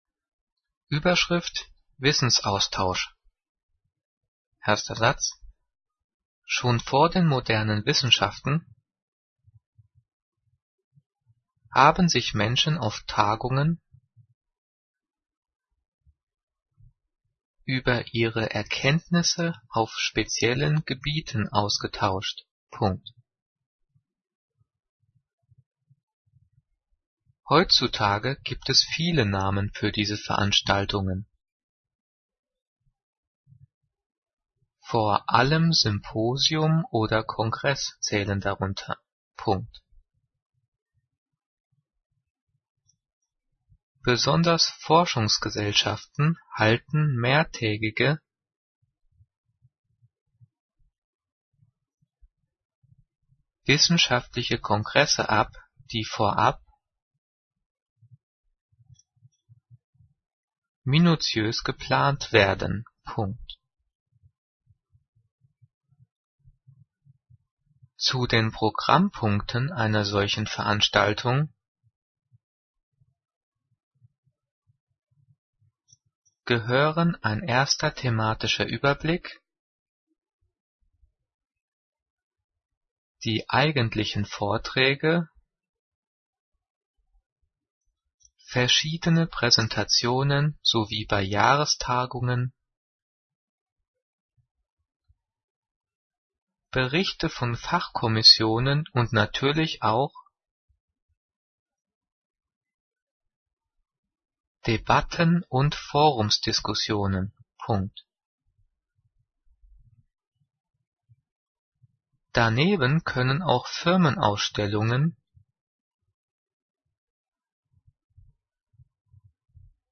Diktat: "Wissensaustausch" - 9./10. Klasse - s-Laute
Die vielen Sprechpausen sind dafür da, dass du die Audio-Datei pausierst, um mitzukommen.
Übrigens, die Satzzeichen werden außer beim Thema "Zeichensetzung" und den Übungsdiktaten der 9./10. Klasse mitdiktiert.
Diktiert: